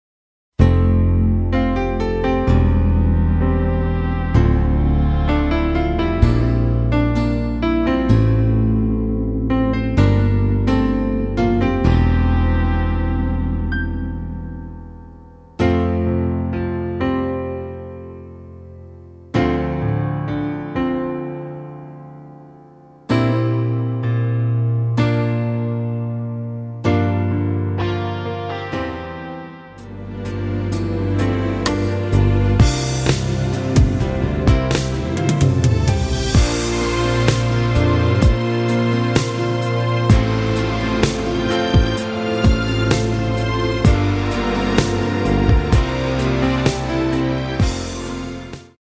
앞부분30초, 뒷부분30초씩 편집해서 올려 드리고 있습니다.
중간에 음이 끈어지고 다시 나오는 이유는
위처럼 미리듣기를 만들어서 그렇습니다.